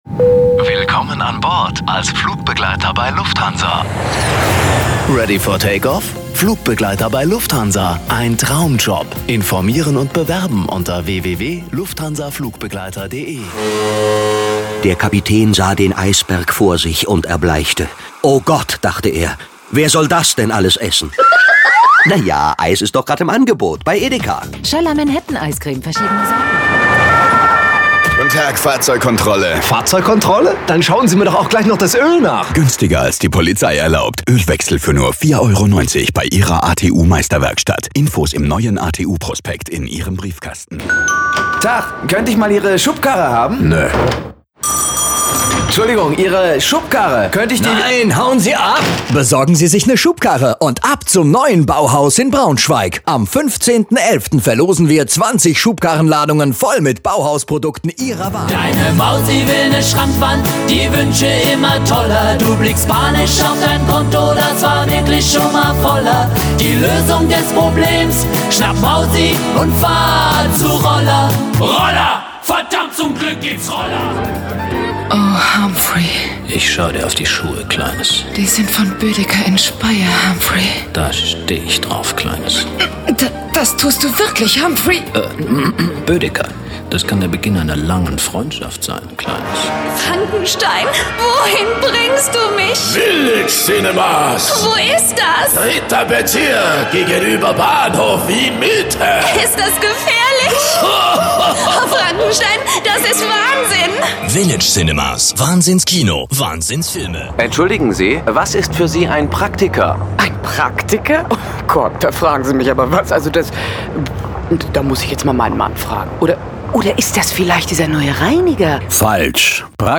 Im Player: der Dreieinhalb-Minuten-Werbeblock